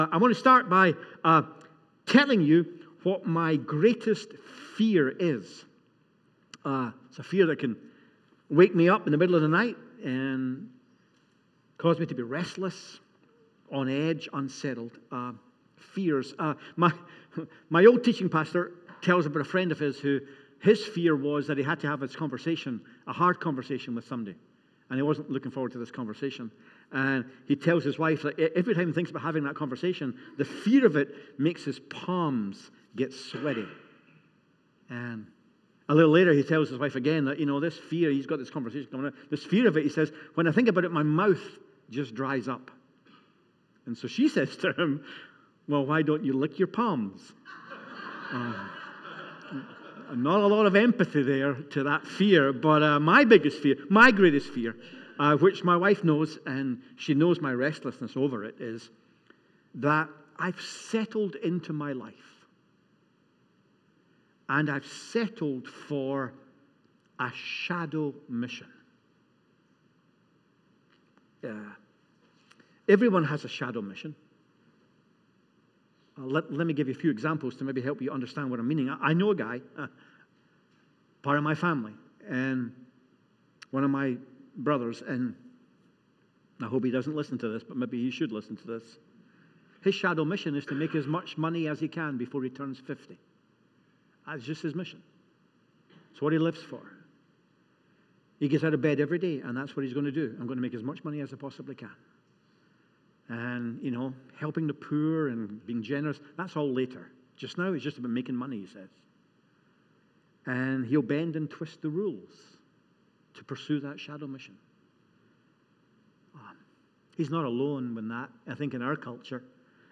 Sunday Service 02.23.25